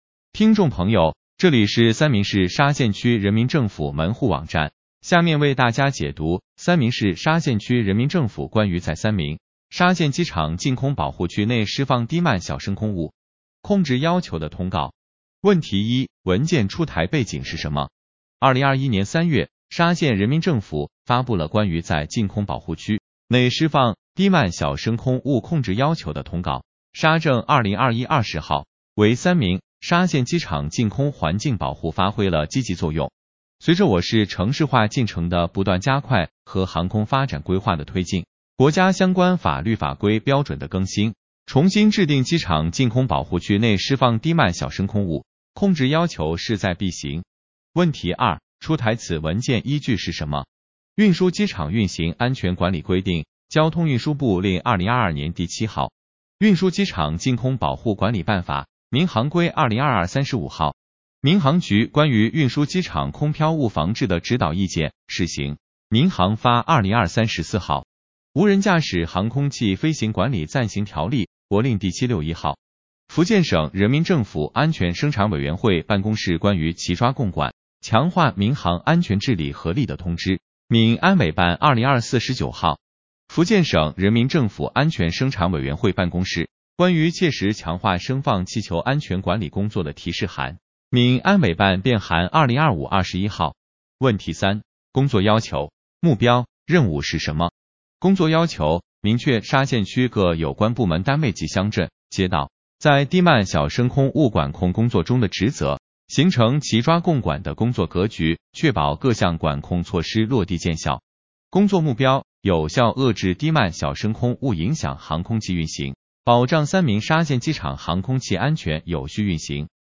音频解读：三明市沙县区人民政府关于在三明沙县机场净空保护区内施放“低慢小”升空物控制要求的通告